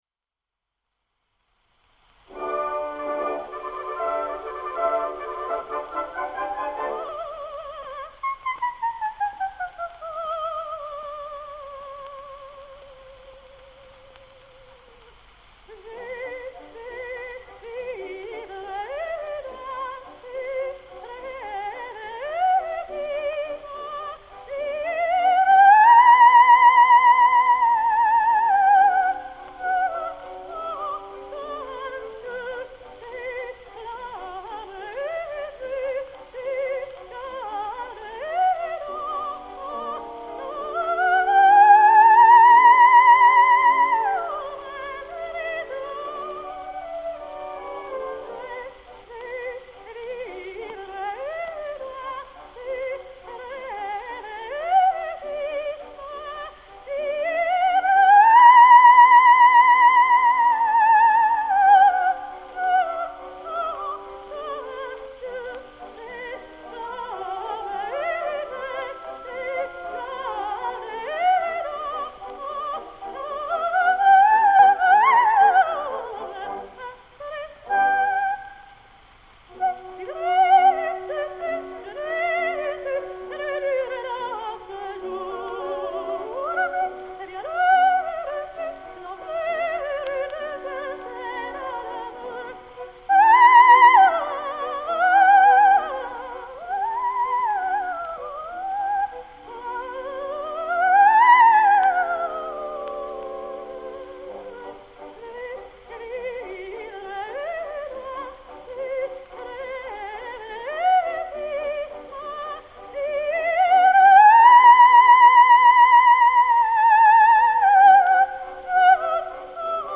Амелита Галли-Курчи (сопрано)